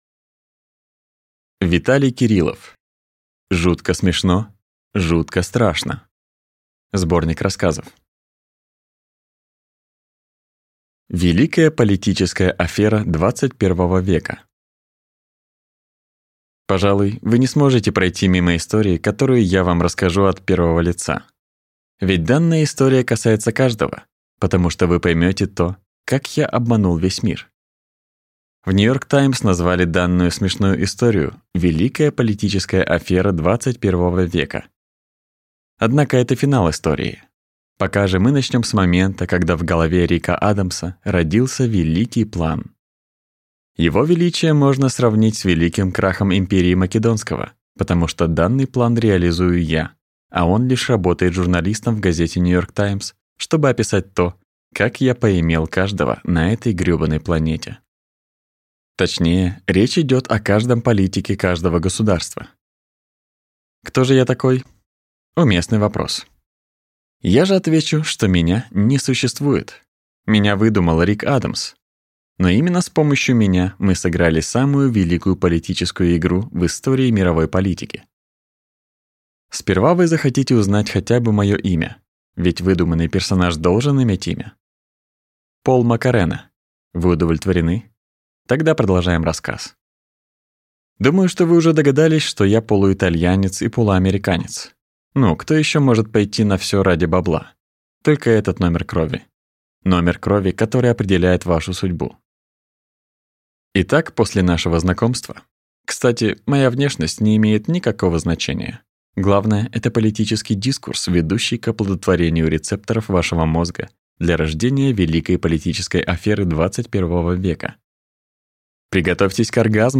Аудиокнига Жутко смешно, жутко страшно! Сборник рассказов | Библиотека аудиокниг